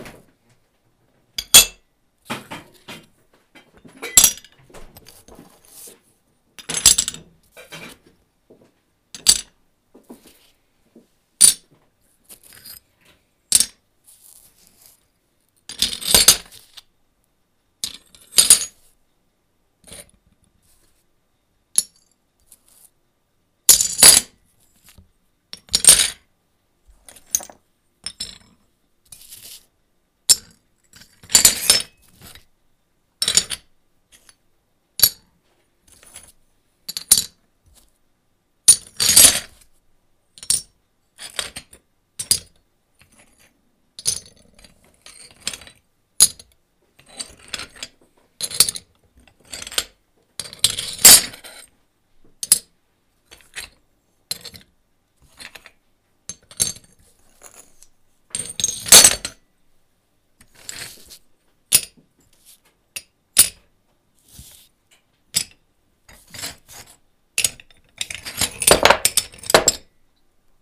Esialgne näide helitaustast: